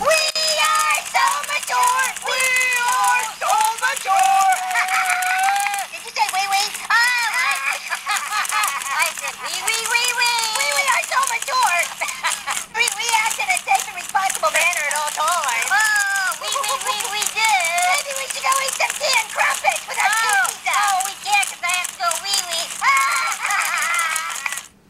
We Are So Mature! is a hoops&yoyo greeting card with motion and sound.
Card sound